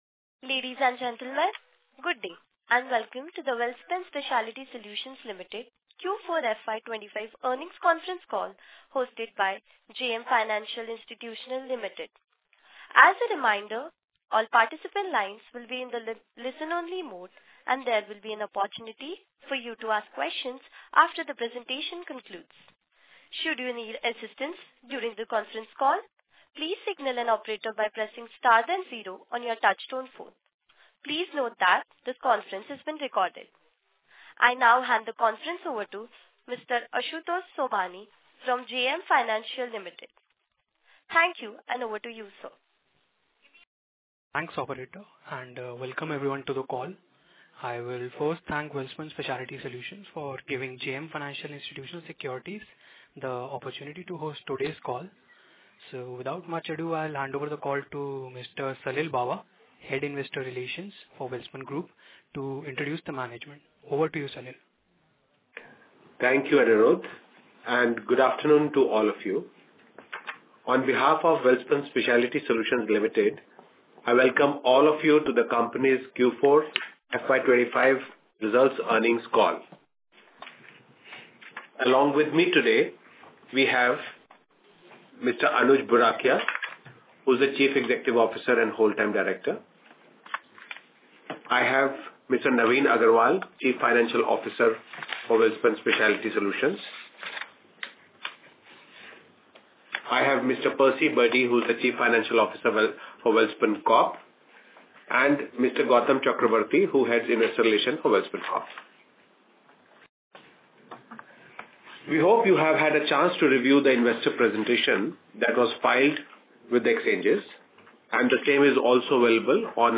WSSL_Q4&FY25_EarningsCall_Recording.mp3